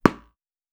Wall Hit Intense.wav